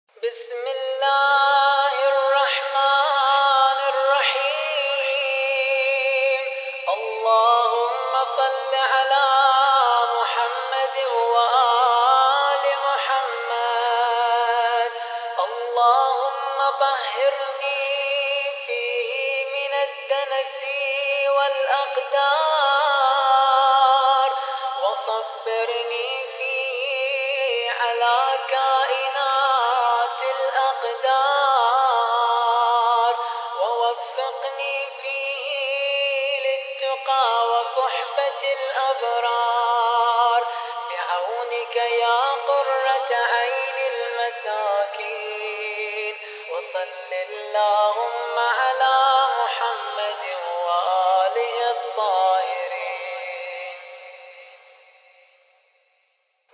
الخطیب: الرادود